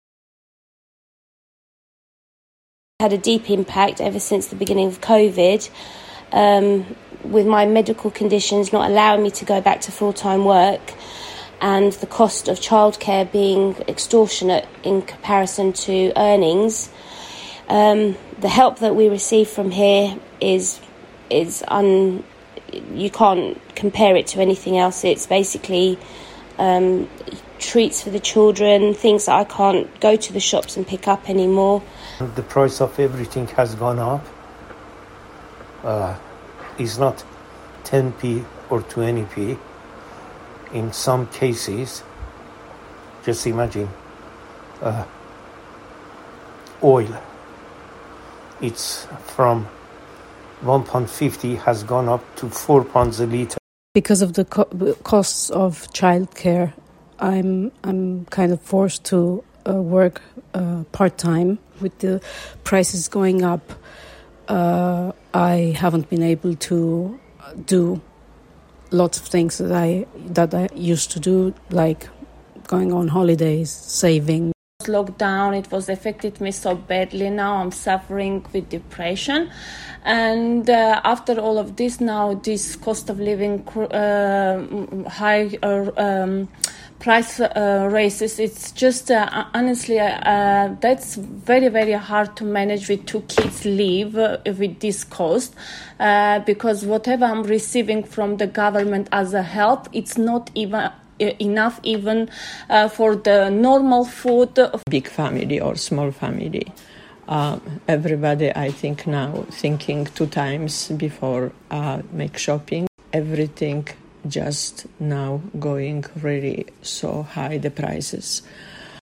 Listen to the voices of the Armenian community captured by Centre for Armenian Information and Advice